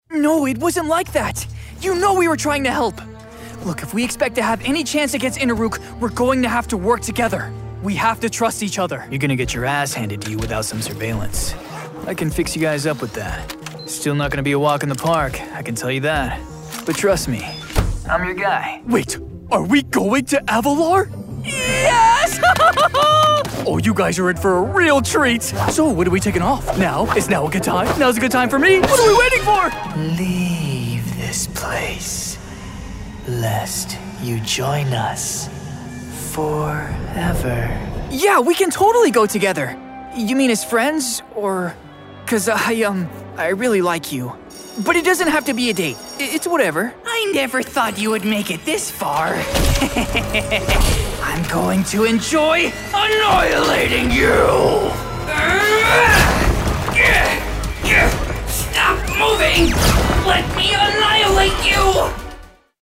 Youthful Videogame Demo